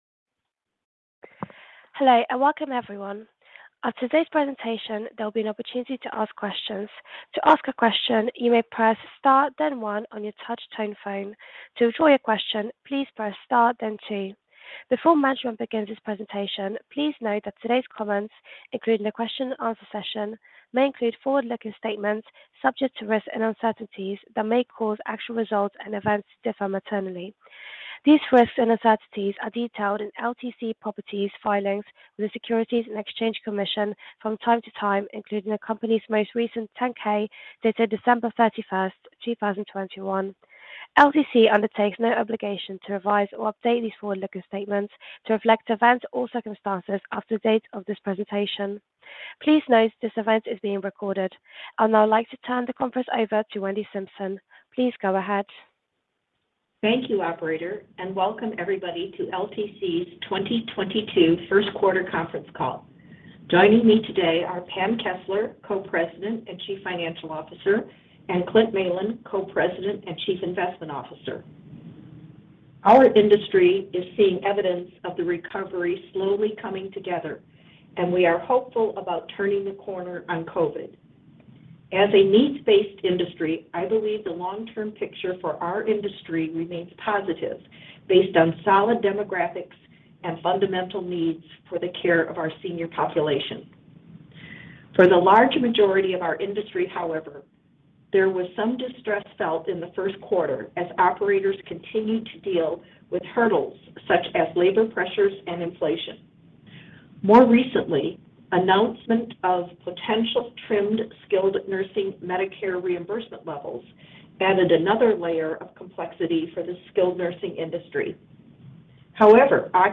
Earnings Webcast Q1 2022 Audio
LTC-1Q22-Analyst-and-Investor-Call-Audio.mp3